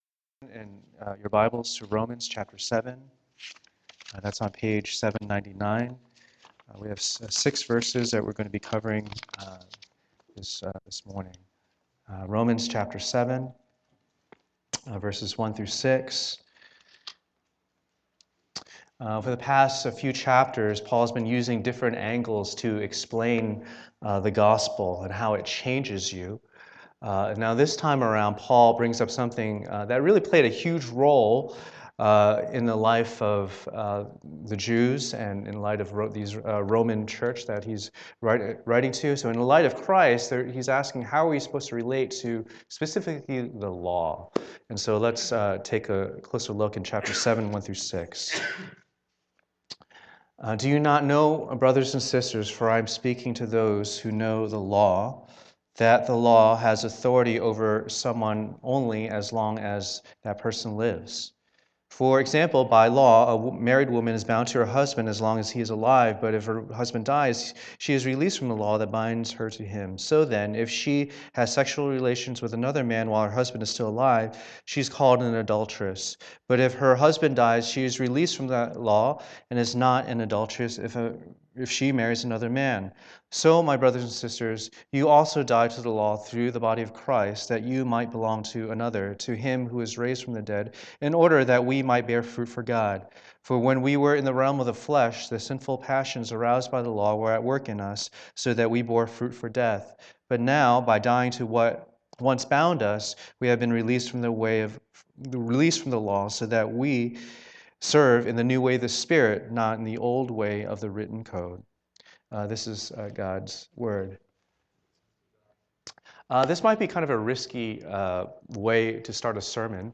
Service Type: Lord's Day